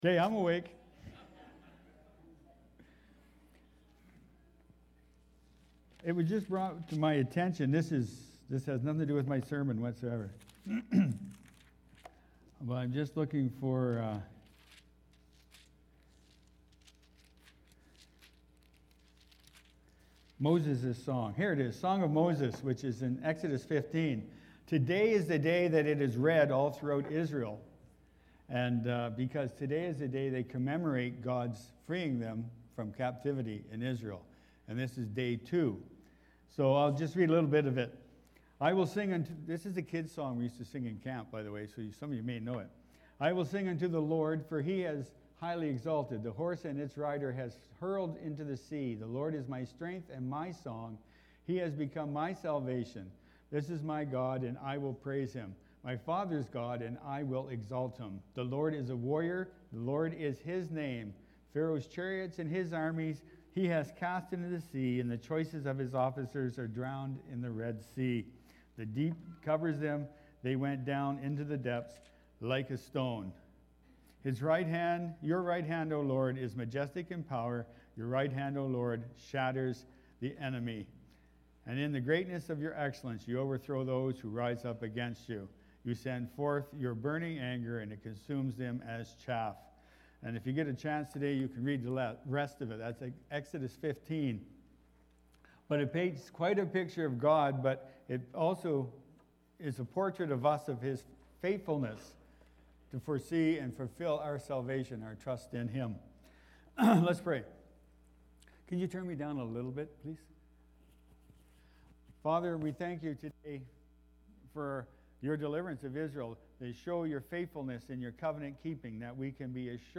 Missing-Christ-Sermon-Audio-.mp3